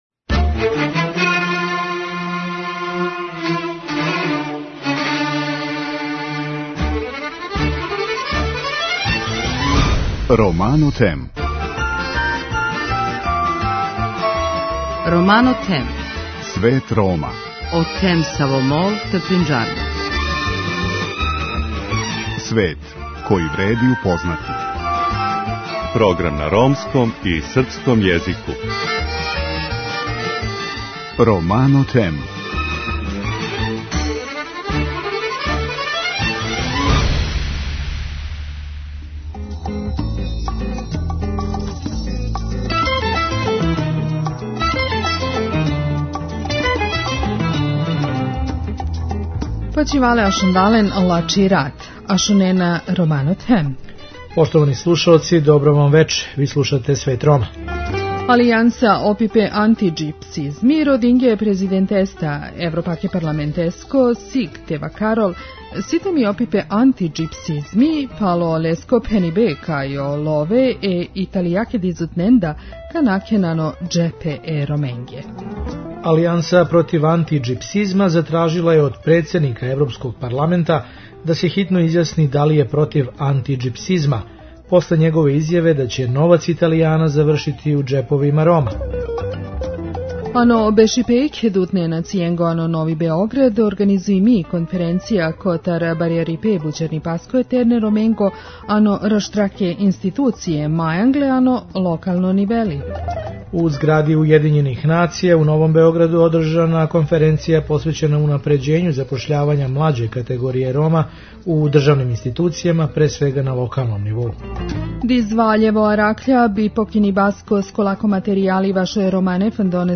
У репортажи коју емитујемо у другом делу емисије говоримо о ромским краљевским династијама у Шкотској које су опстајале пуних пет векова.